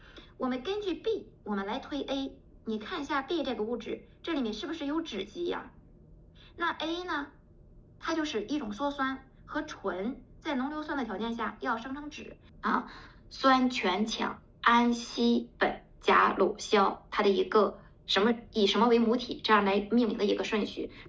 最后是化学课程的一段音频。识别结果中酯基、酸、醛、氨等化学名词，以及音频中人物的语气词识别并未出错。